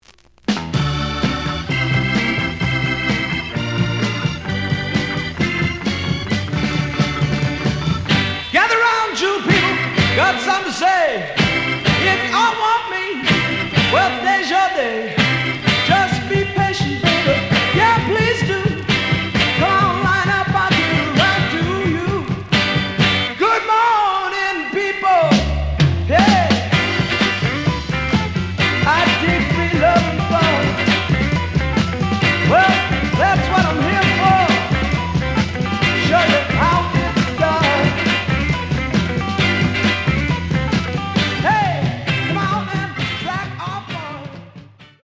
The audio sample is recorded from the actual item.
●Genre: Rock / Pop
Slight edge warp. But doesn't affect playing.